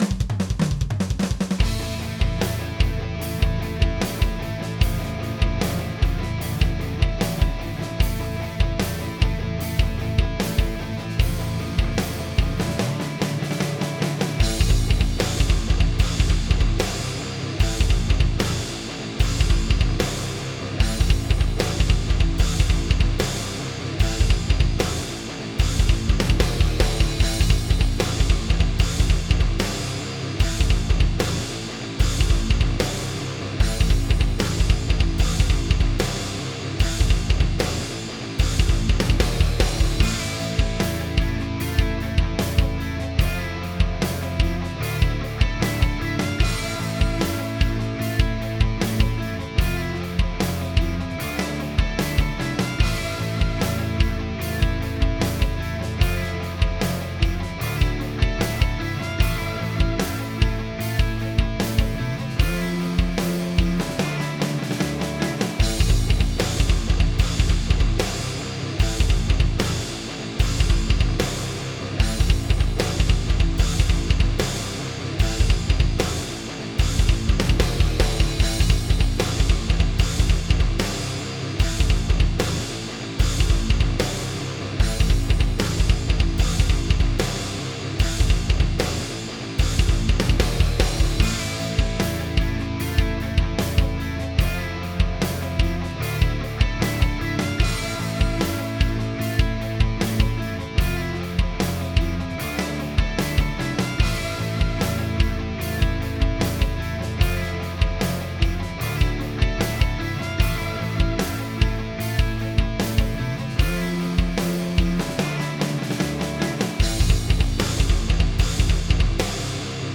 Konkursowe podkłady muzyczne:
PODKŁAD 3,